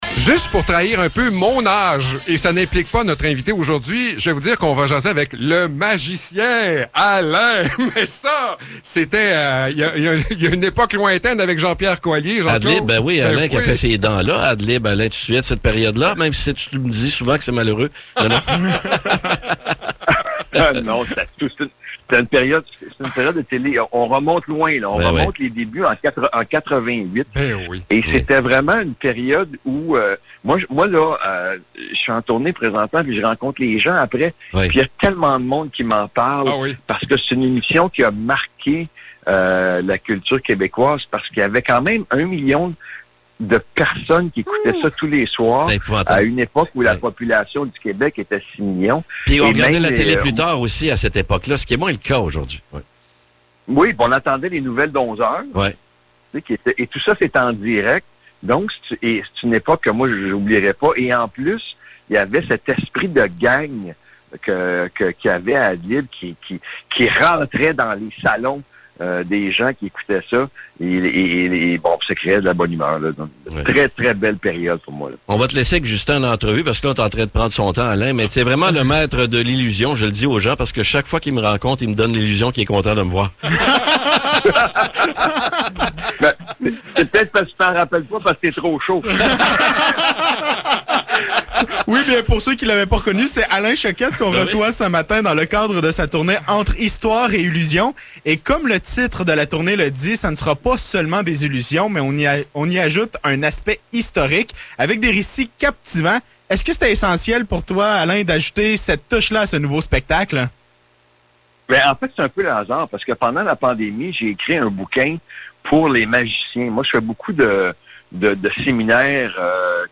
Entrevue avec Alain Choquette
ENTREVUE-ALAIN-CHOQUETTE.mp3